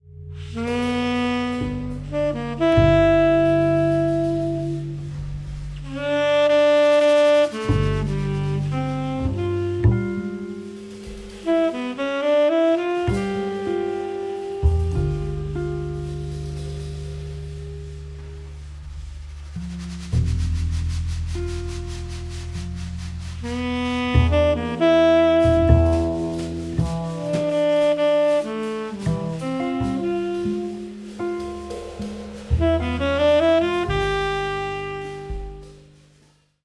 guitar
tenor saxophone
bass
drums